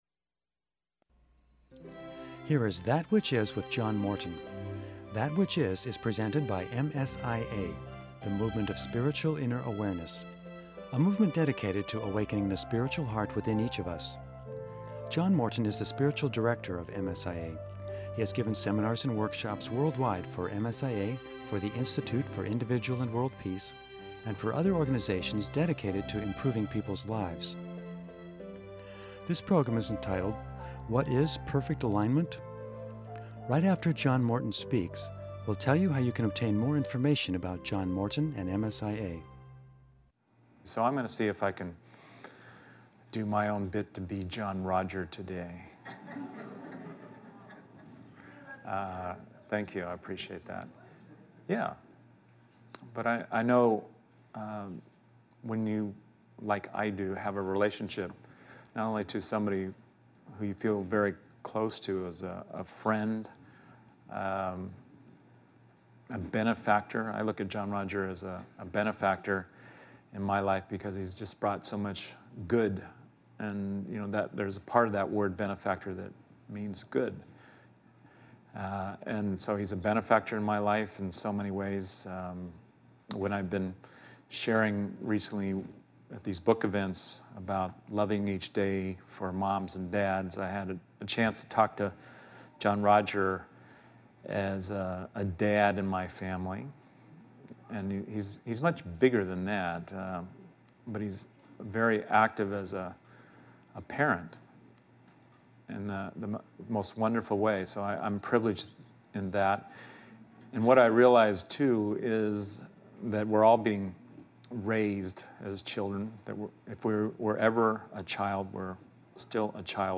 In this especially sweet talk